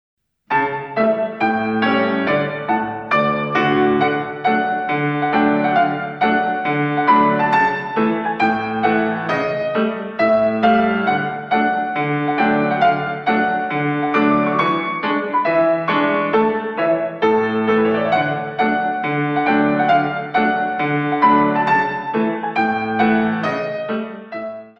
In 2